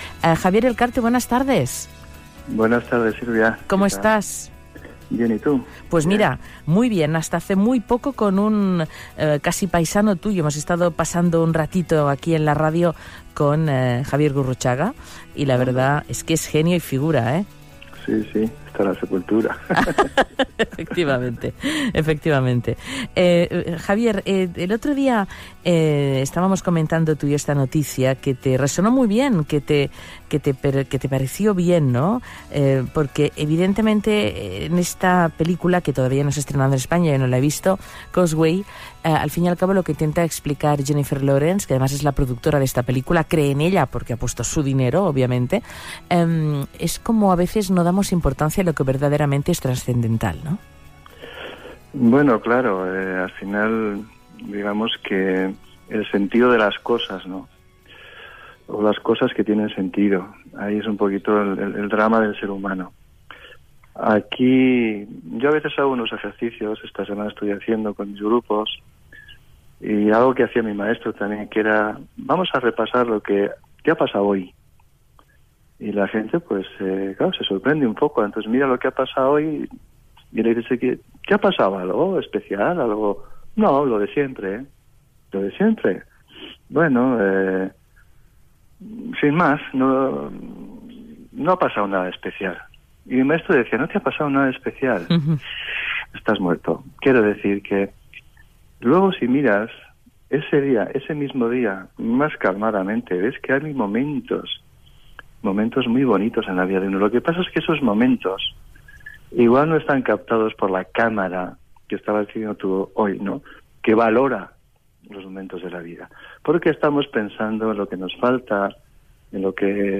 de Radio Nacional de España/Radio4
ambos charlan sobre que debemos aprender a darnos cuenta de los pequeños momentos que realmente son relevantes en nuestro día a día. No estamos presentes, estamos viviendo a un nivel mental con nuestros miedos, expectativas, etc…